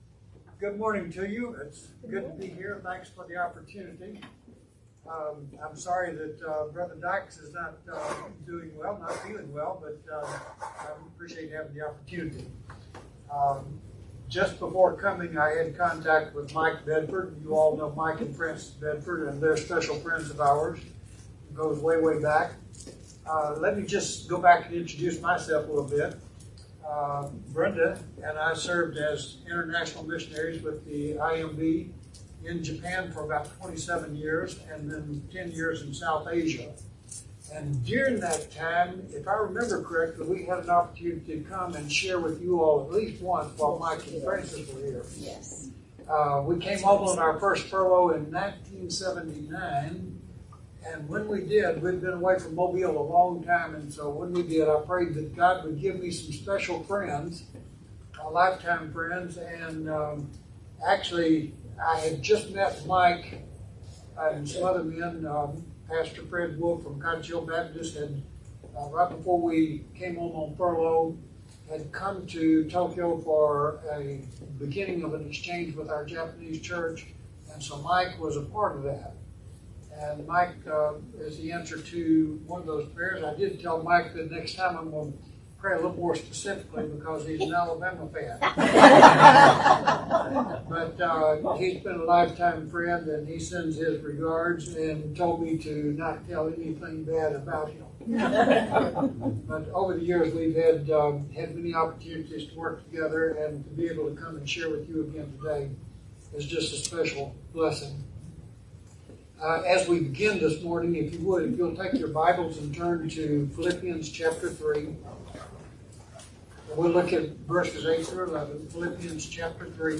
sermon-6-26-22.mp3